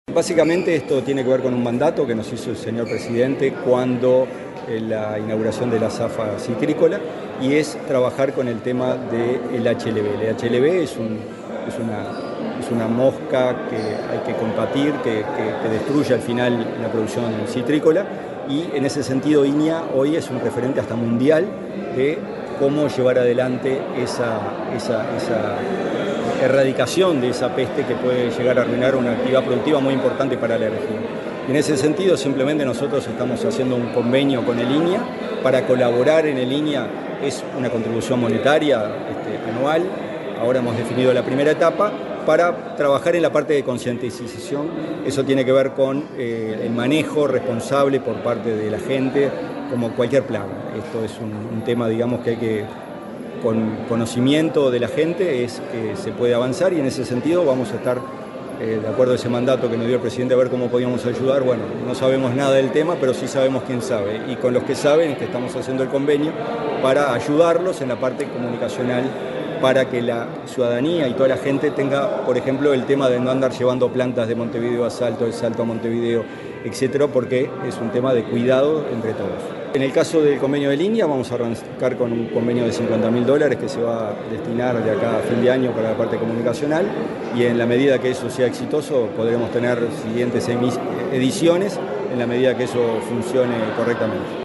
El titular de la delegación de Uruguay en la Comisión Técnica Mixta de Salto Grande (CTM), Gonzalo Casaravilla, realizó declaraciones en la firma de un convenio con el Instituto Nacional de Investigación Agropecuaria (INIA) para desarrollar una campaña de sensibilización en torno a una enfermedad bacteriana que afecta los cítricos.